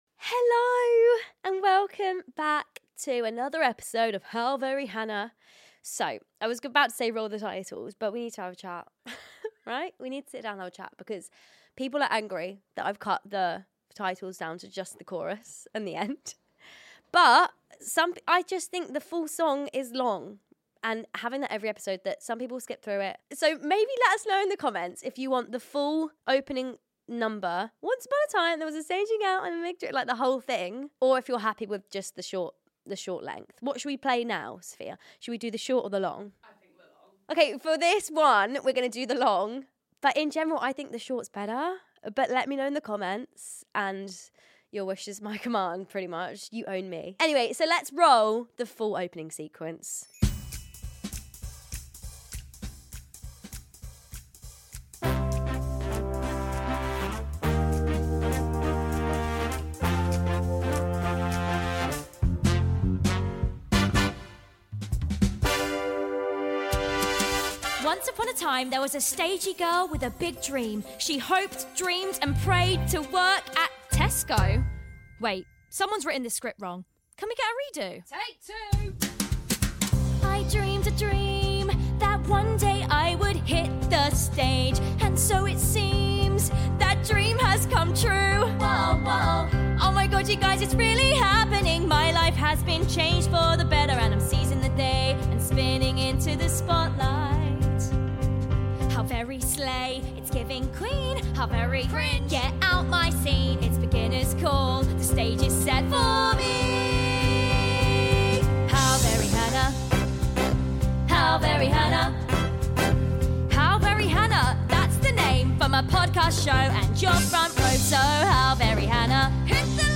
It’s a solo episode this week… and you’re getting an EXCLUSIVE!! because I’m finally telling the full story of how I got engaged 💍✨